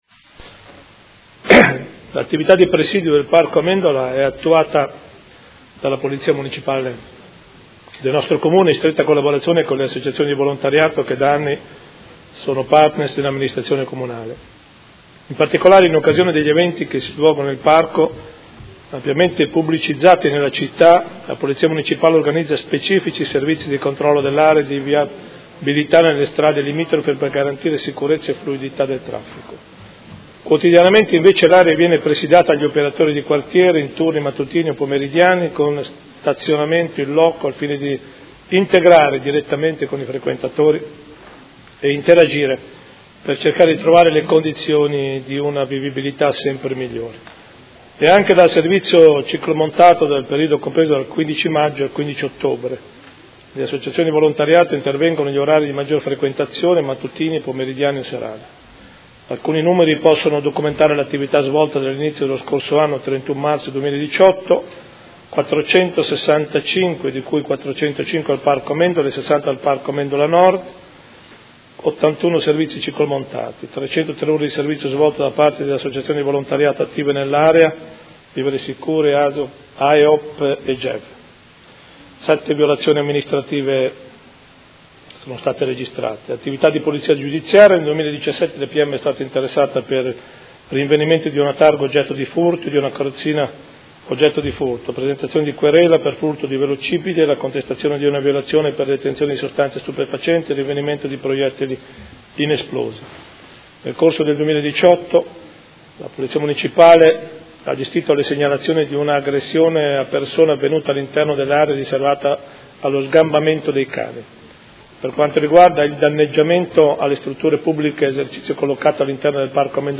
Seduta del 19/04/2018. Risponde a interrogazione dei Consiglieri Arletti e Fasano (PD) avente per oggetto: Sicurezza al Parco Amendola: quali misure per evitare vandalismi nella struttura centrale a servizio del Parco?
Sindaco